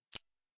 通用点击.ogg